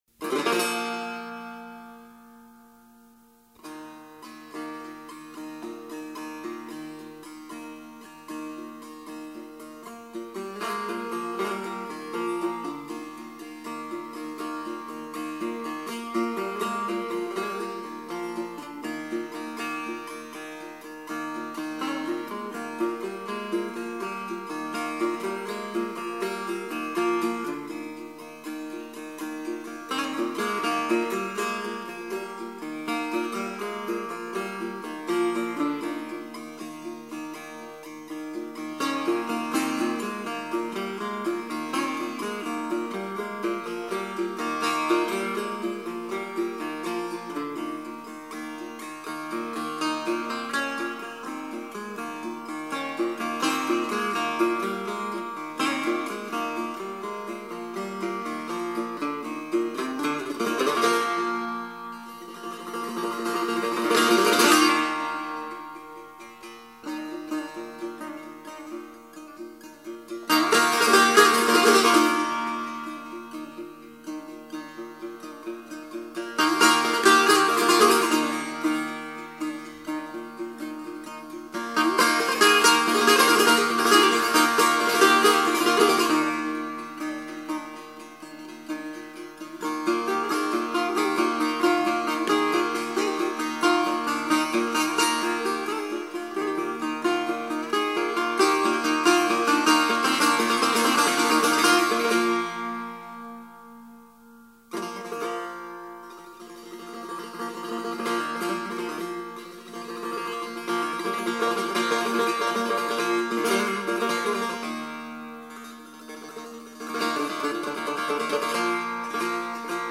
تک نوازی سه تار